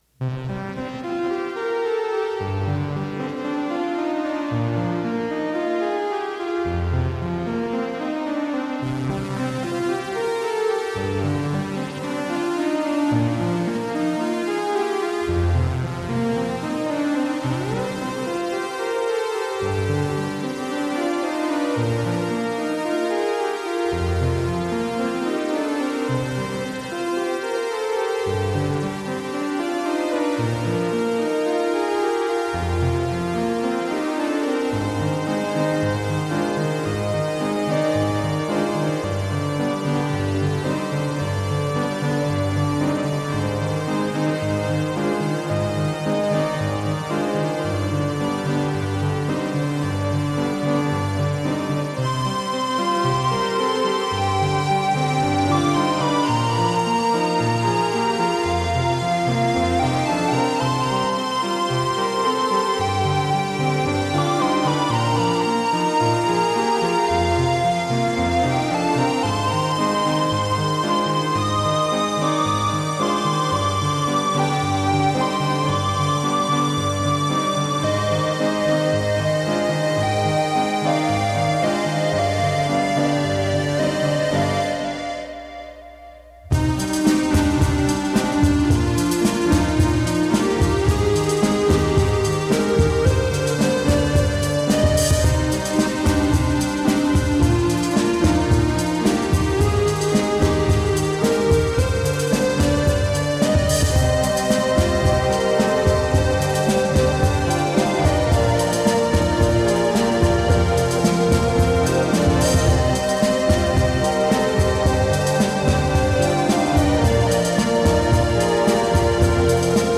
Melodic electronica.
Tagged as: Electronica, Other, IDM, Prog Rock